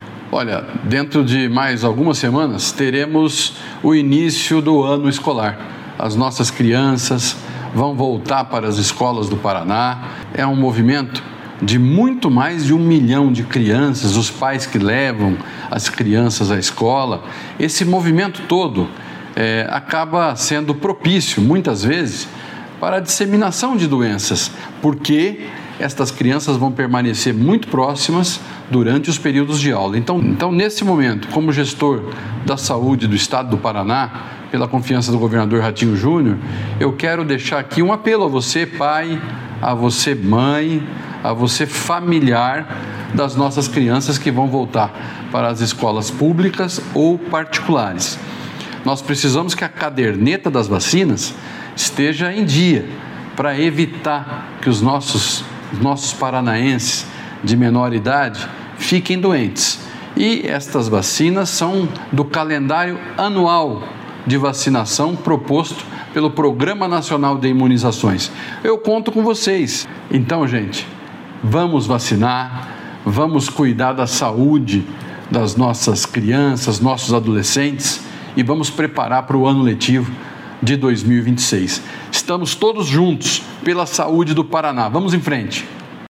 Sonora do secretário da Saúde, Beto Preto, sobre a importância da vacinação de crianças e adolescentes na volta às aulas